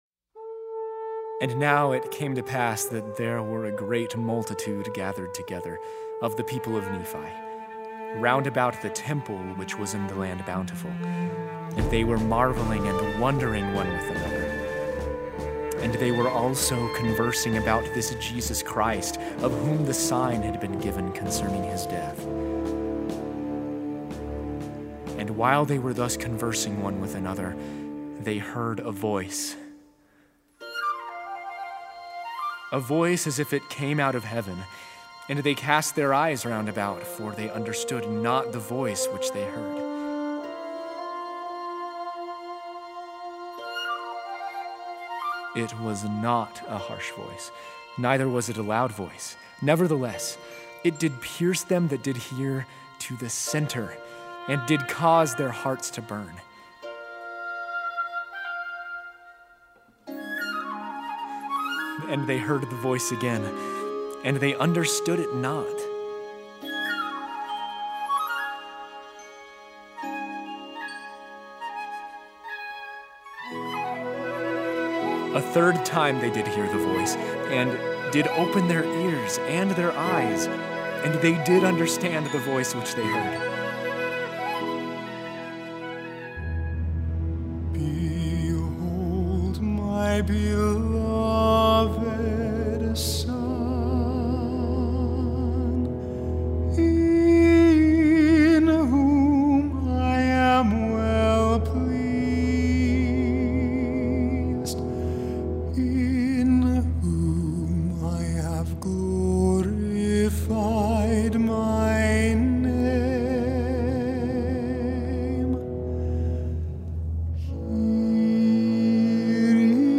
Tenor, Baritone, Baritone, SATB, and Full Orchestra